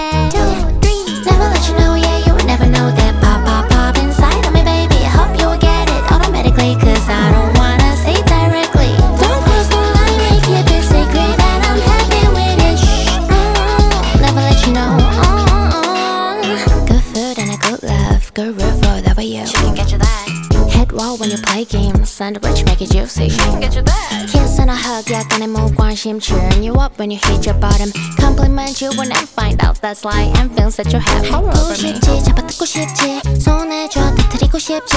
Жанр: Поп / R&b / K-pop / Соул